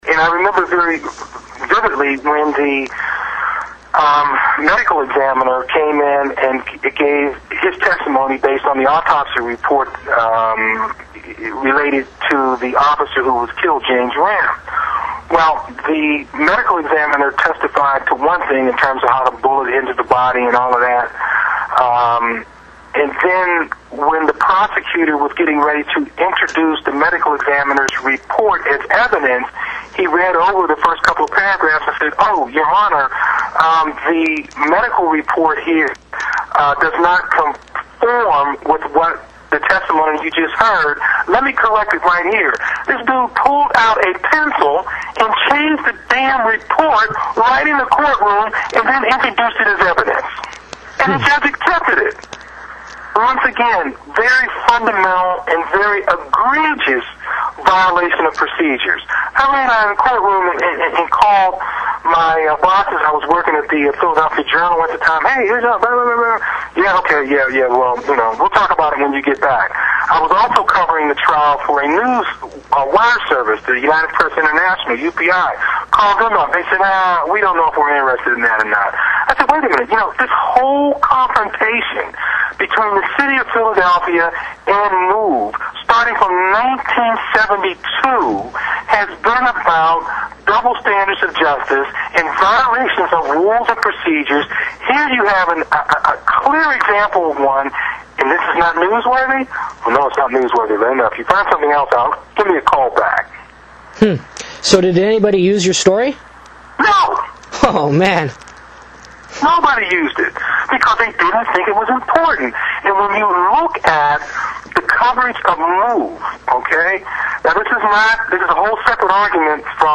Speaking on behalf of The Southern Christian Leadership Conference, Martin Luther King III speaks out for both Mumia Abu-Jamal and The MOVE 9 political prisoners from Philadelphia.